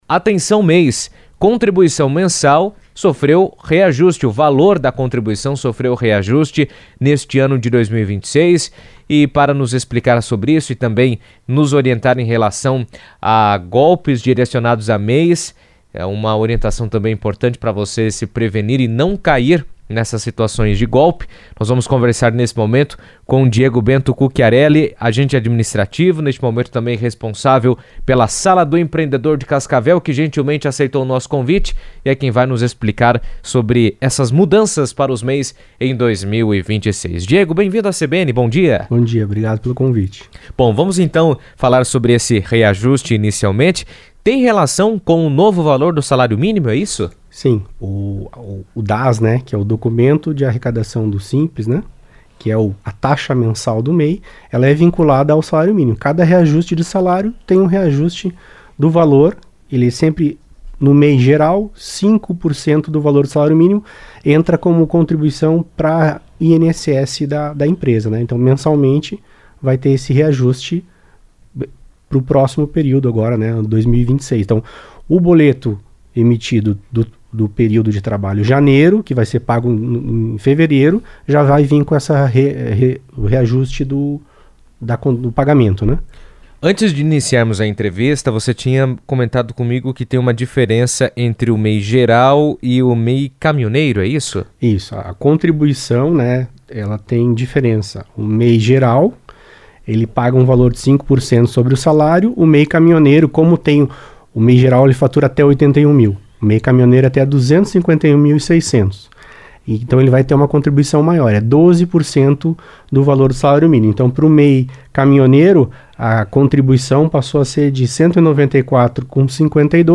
Em 2026, a contribuição mensal do Microempreendedor Individual (MEI) foi reajustada para R$ 81,05, em decorrência do aumento do salário mínimo. Ao mesmo tempo, a Sala do Empreendedor alerta os MEIs sobre mensagens fraudulentas, principalmente por WhatsApp, que se passam por comunicados da Receita Federal e informam suposta suspensão, bloqueio ou cancelamento do cadastro, golpes que não correspondem à realidade. Em entrevista à CBN